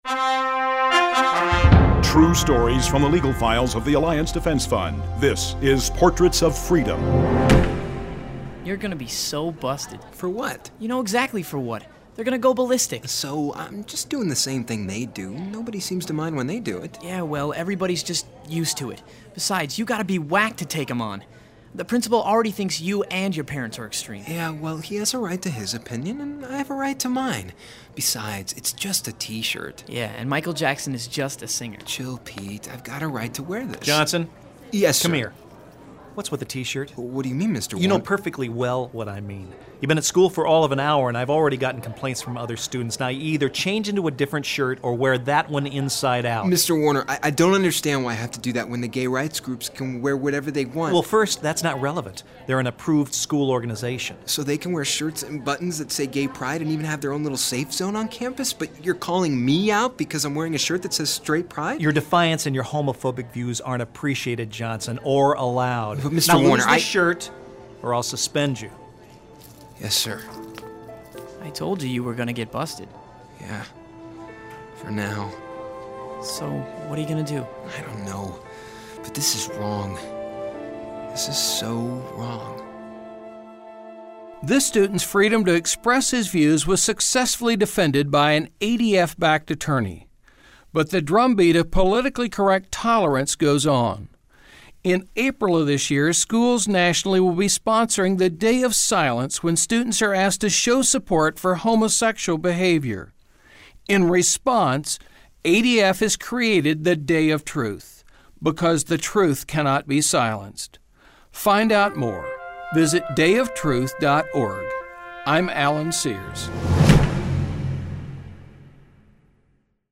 Alliance Defense Fund Radio Clip: